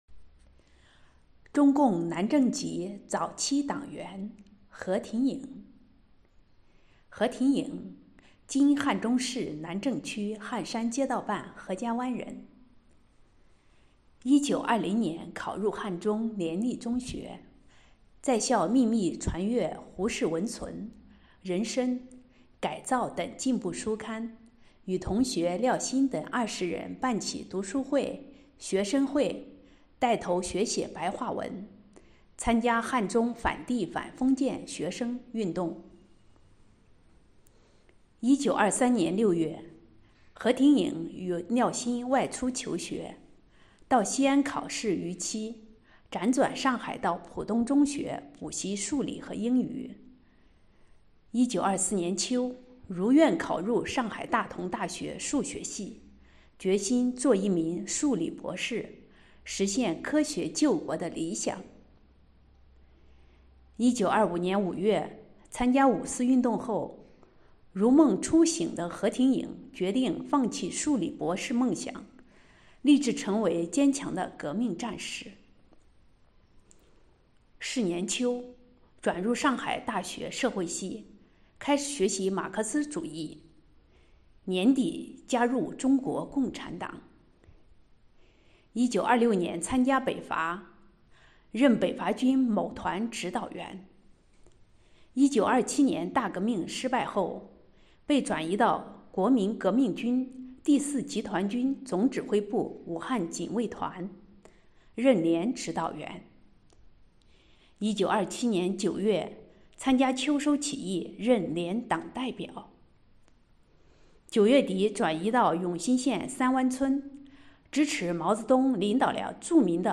【红色档案诵读展播】中共南郑籍早期党员——何挺颖